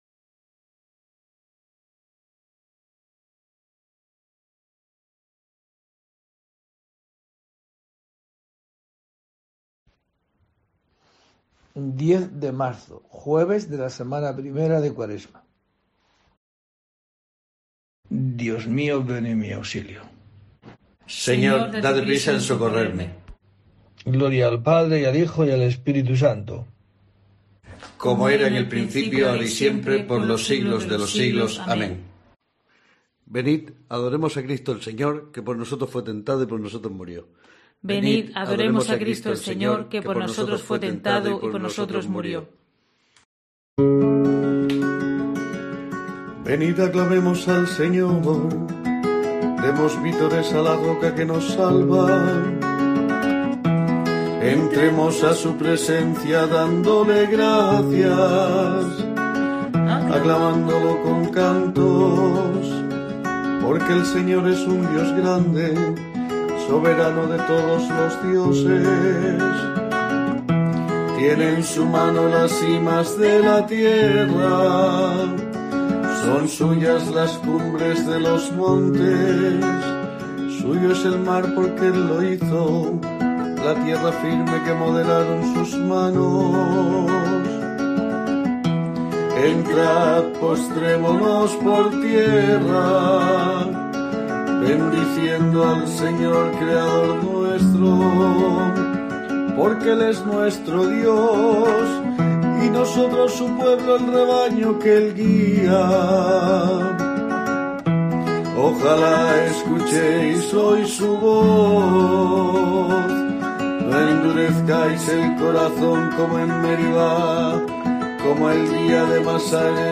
10 de marzo: COPE te trae el rezo diario de los Laudes para acompañarte